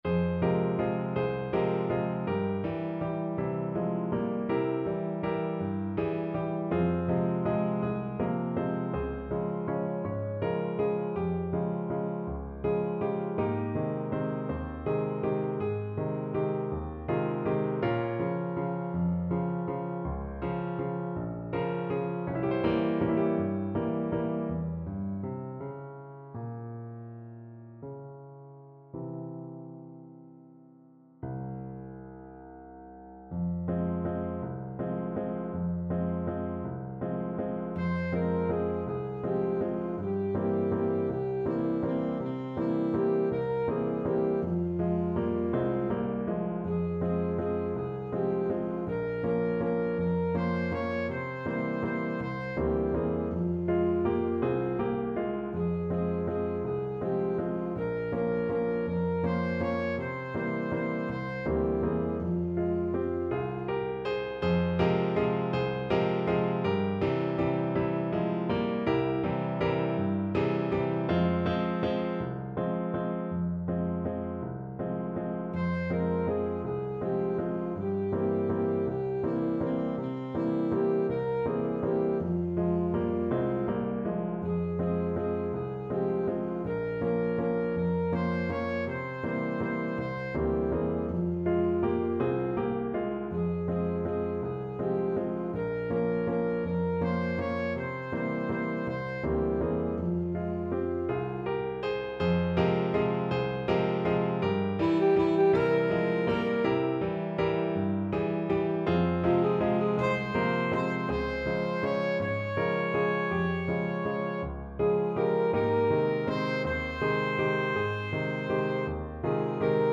Alto Saxophone
One in a bar .=c.54
3/4 (View more 3/4 Music)
Classical (View more Classical Saxophone Music)